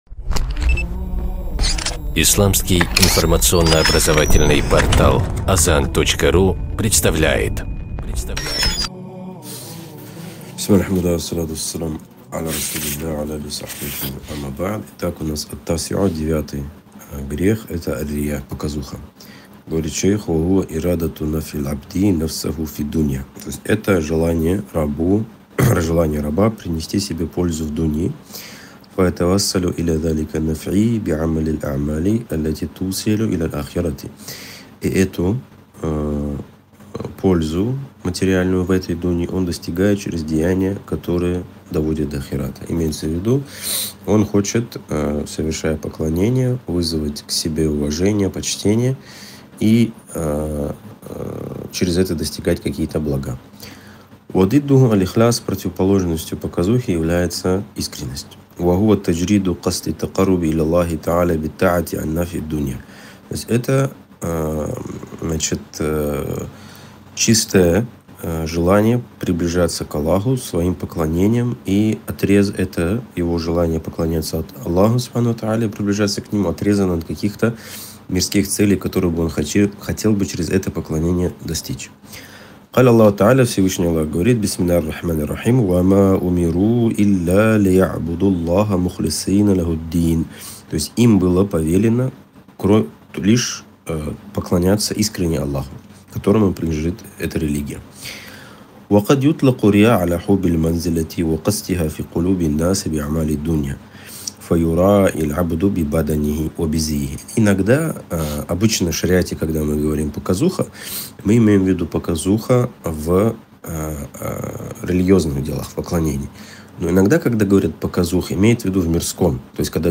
ЦИКЛЫ УРОКОВ